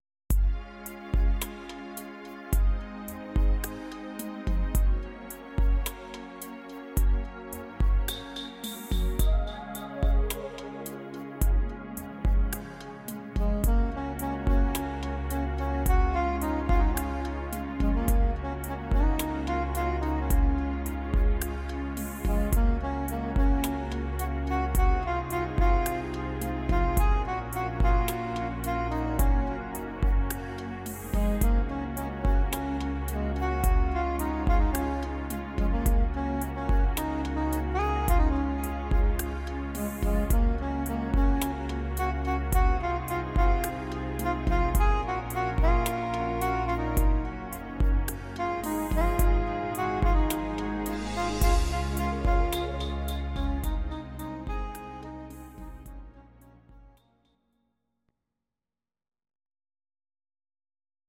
Audio Recordings based on Midi-files
Pop, 1990s